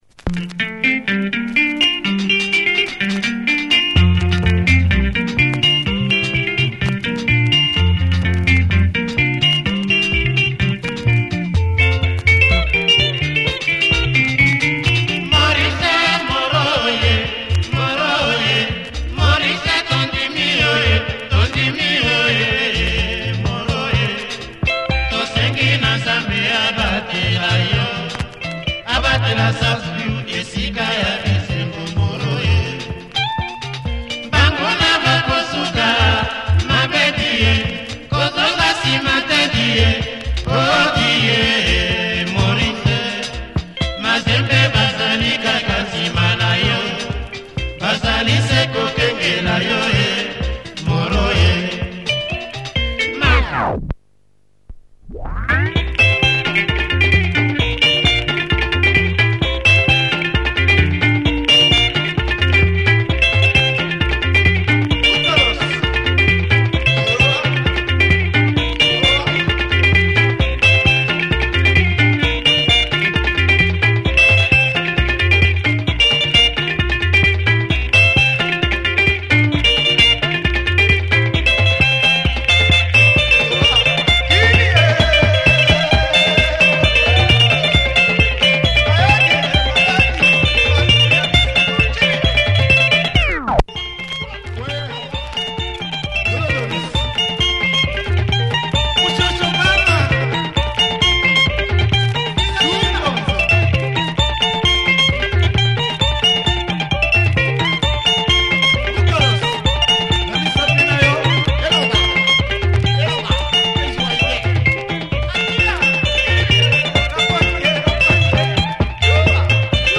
looong breakdown. https